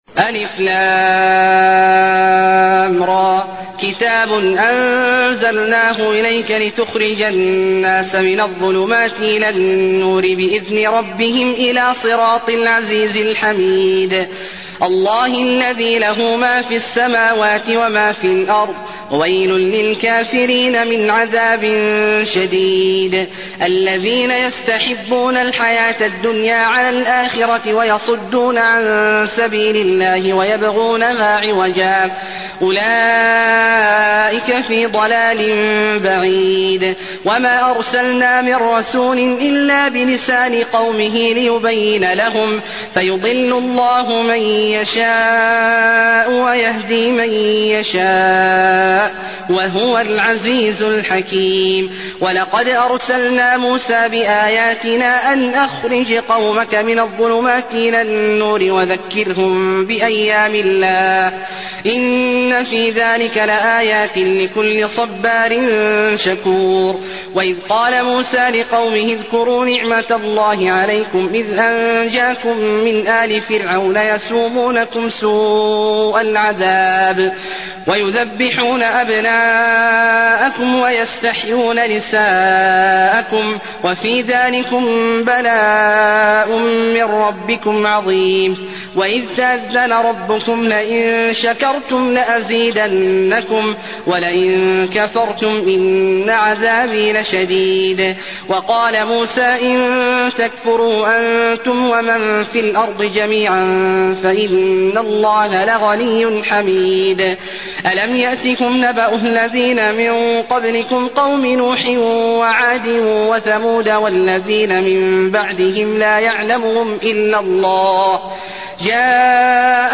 المكان: المسجد النبوي إبراهيم